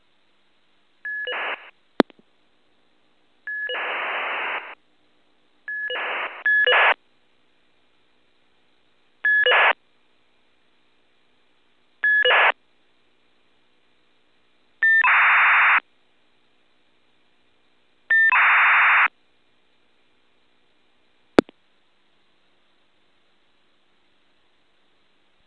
Premier enregistrement de réponses du Minitel en V.29 puis V.27ter